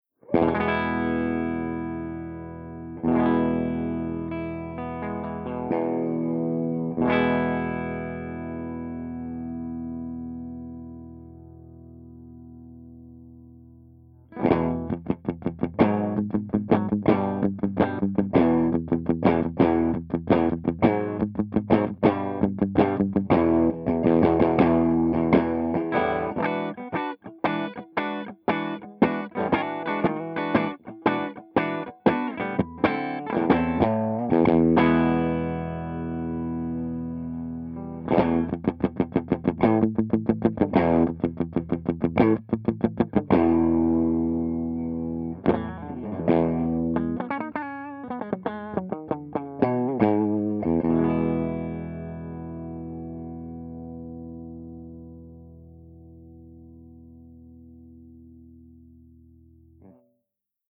075_MARSHALLJTM60_STANDARD_HB.mp3